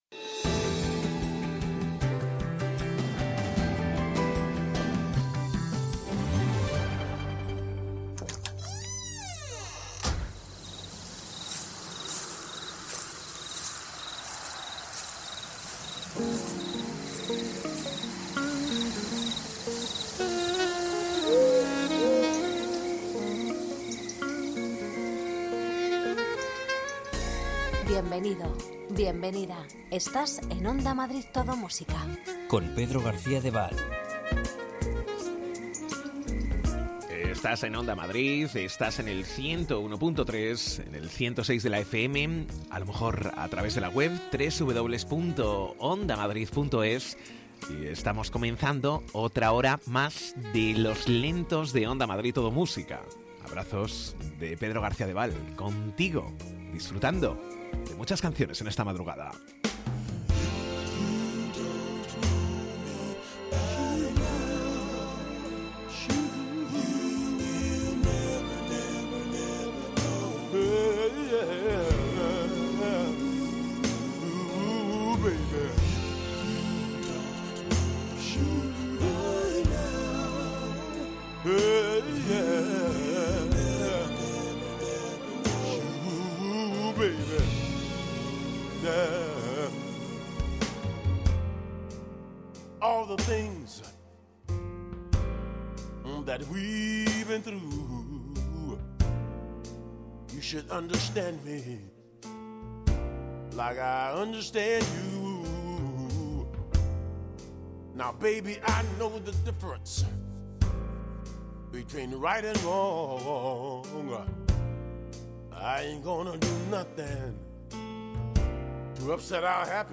Variando en estilos según el momento
De lunes a viernes madrugadas de lentos